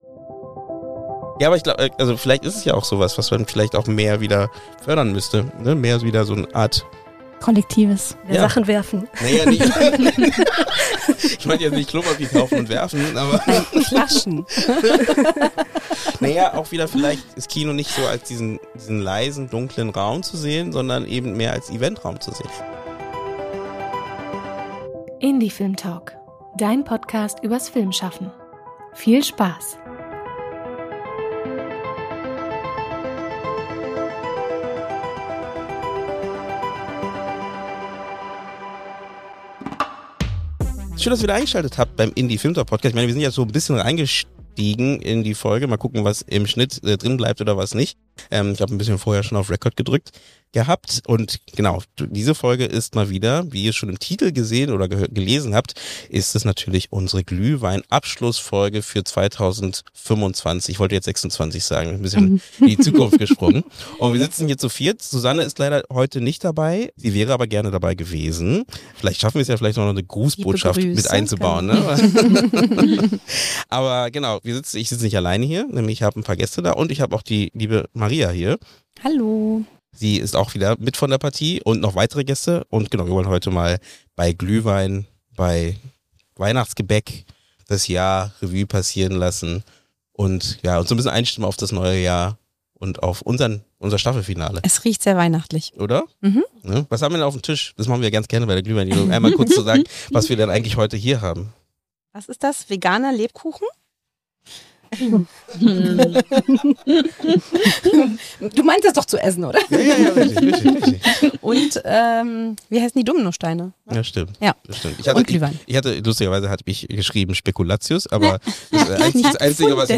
Wer uns schon länger begleitet, kennt das Format: entspannte Roundtable-Gespräche in offener Atmosphäre und mit viel Spaß.